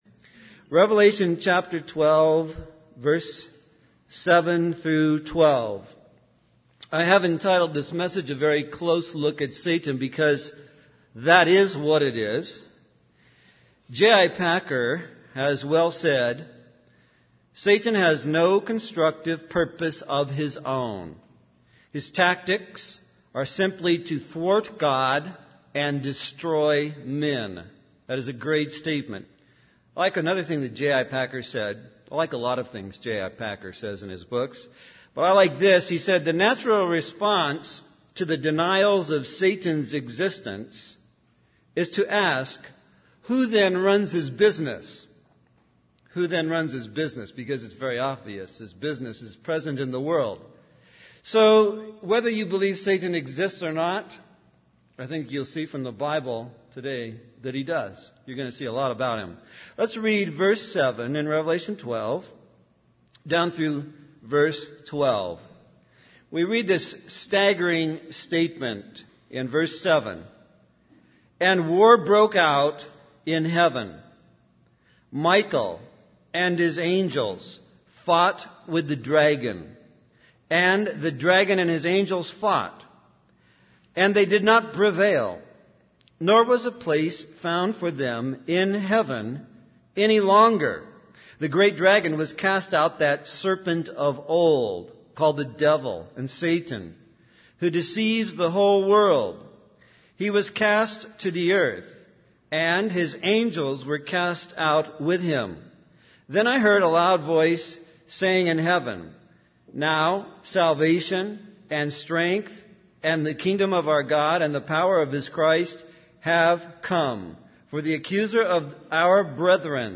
In this sermon, the preacher explores Revelation chapter 12 verses 7-12, focusing on the strategies of Satan and his ultimate defeat. The sermon emphasizes that Satan's tactics are aimed at thwarting God's plans and destroying humanity. The preacher highlights Satan's power to corrupt minds and deceive people, using the example of Eve in the Garden of Eden.